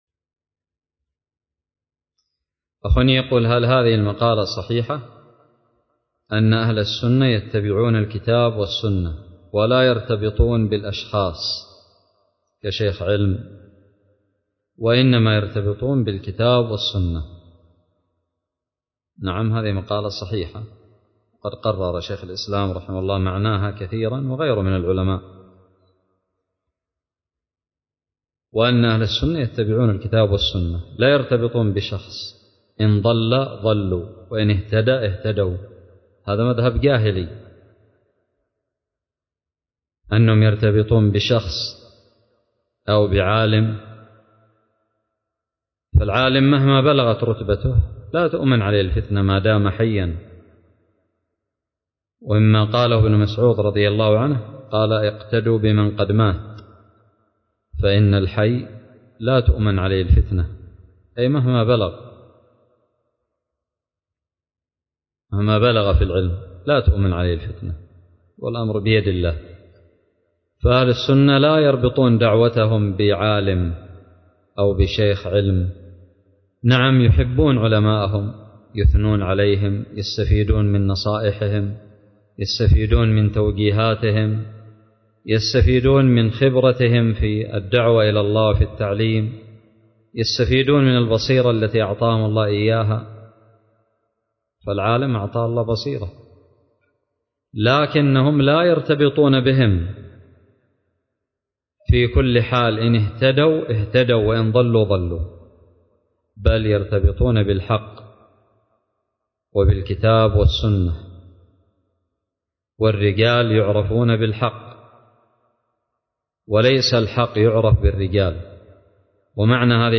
:العنوان فتاوى منهجية :التصنيف 1447-4-2 :تاريخ النشر 44 :عدد الزيارات البحث المؤلفات المقالات الفوائد الصوتيات الفتاوى الدروس الرئيسية هل هذه المقالة صحيحة أن أهل السنة يتبعون الكتاب والسنة ولا يرتبطون بالأشخاص؟